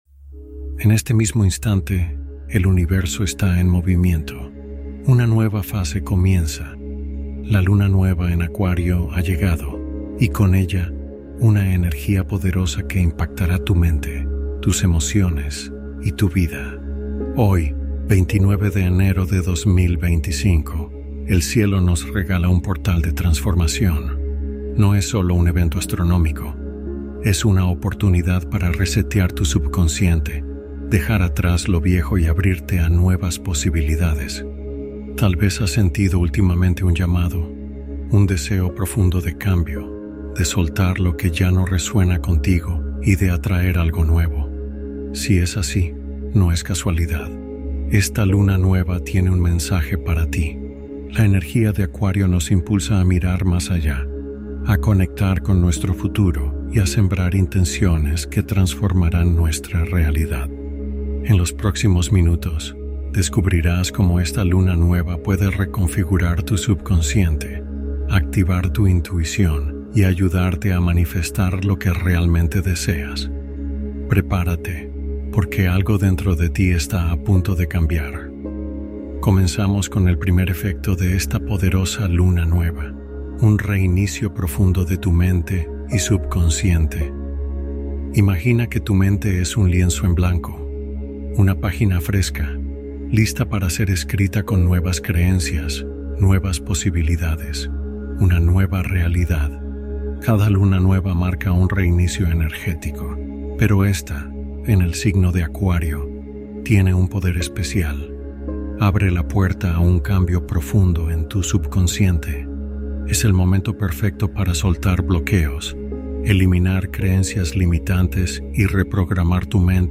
Meditación de apertura interna inspirada en ciclos de renovación